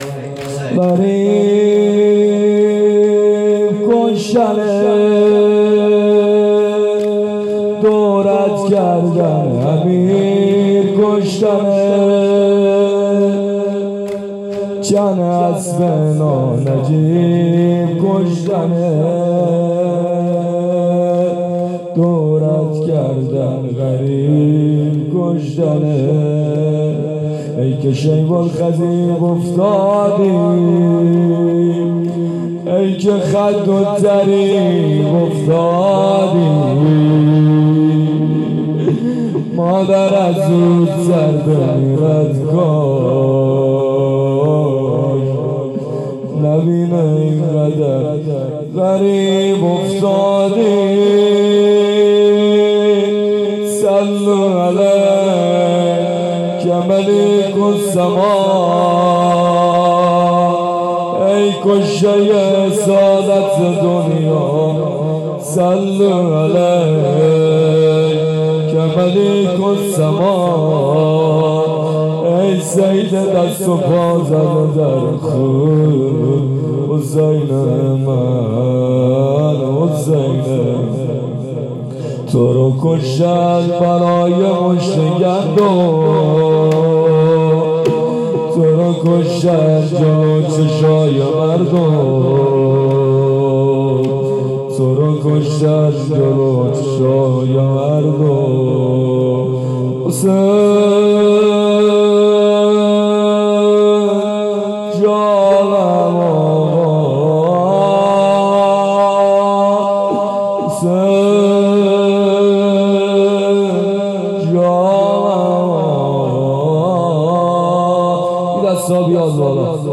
لطمه-زنی-غریب-کشتنت.mp3